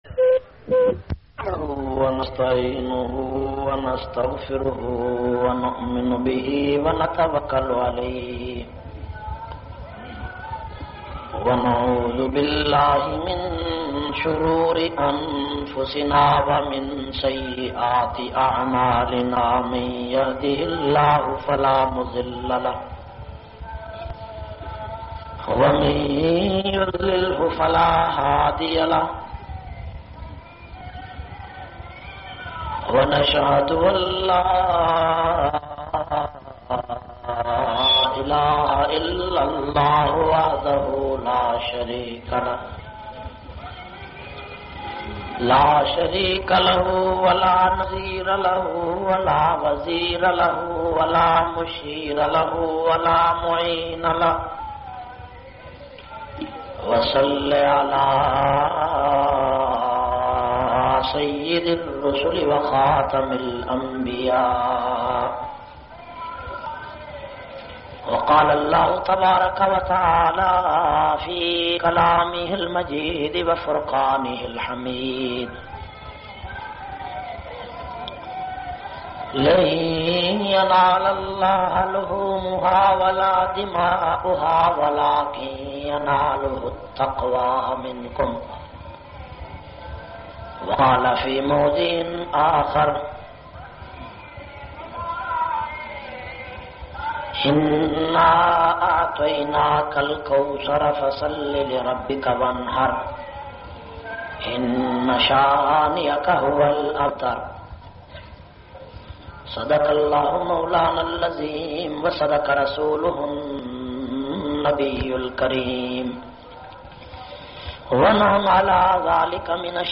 326- Falsfa e Qurbani Eid ul Adha bayan Jhang.mp3